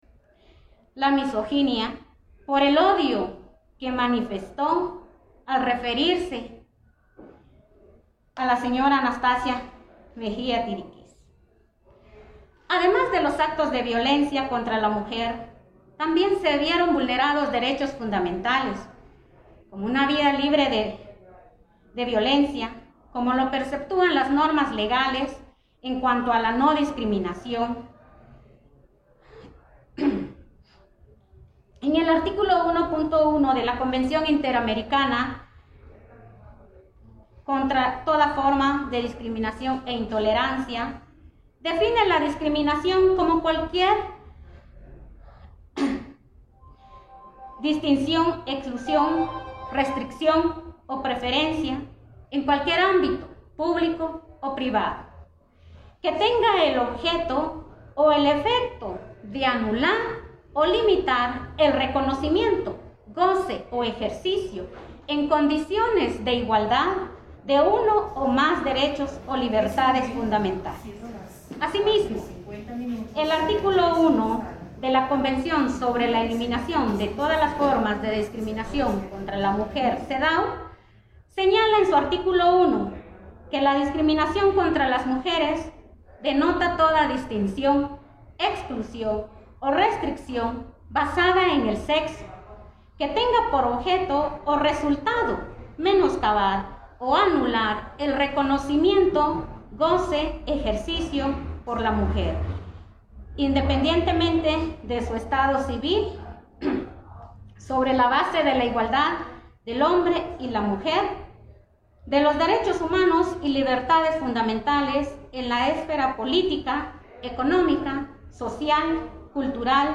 audio-de-la-abogada.mp3